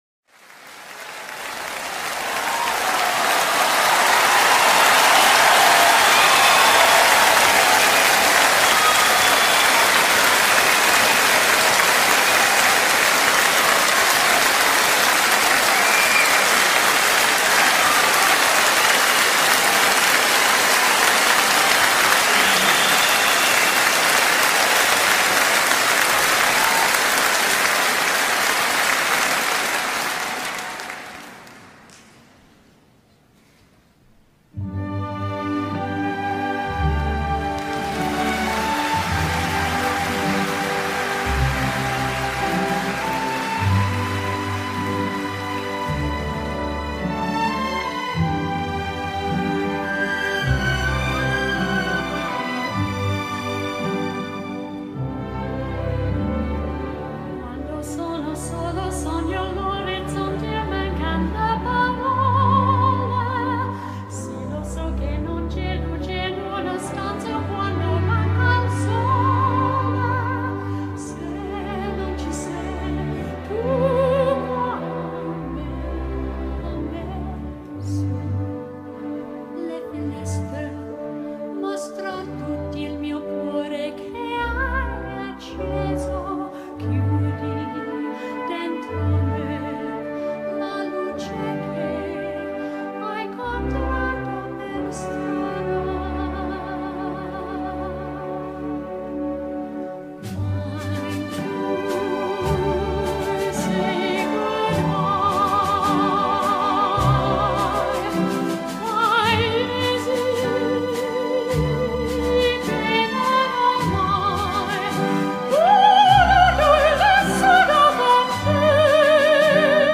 tenor
classical crossover songs
emotional duet